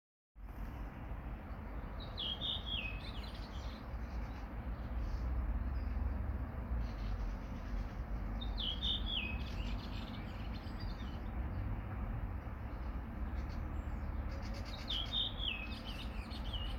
Hvilken fugl er det som synger her?
Dette er en rødvingetrost.